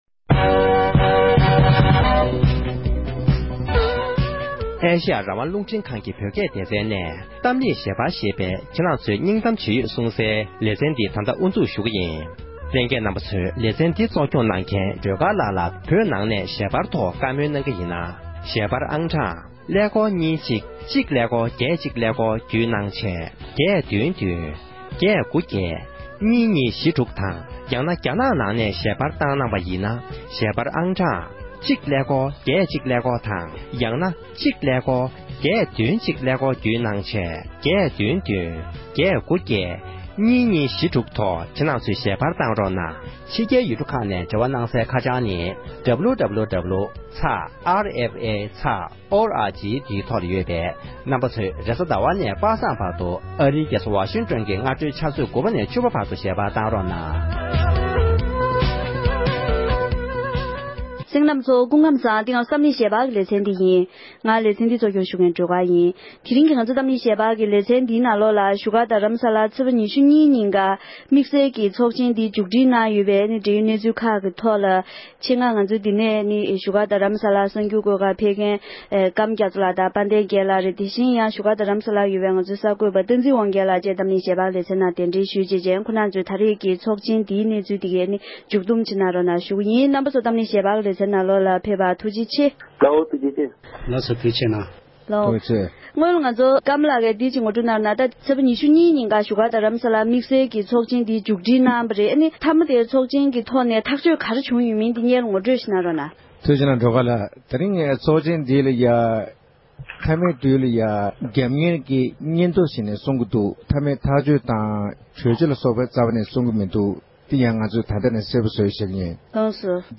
བོད་ཕྱི་ནང་གཉིས་སུ་ཡོད་པའི་བོད་མི་རྣམས་ནས་དམིགས་བསལ་ཚོགས་འདུའི་ཐོག་བགྲོ་གླེང་གནང་བ།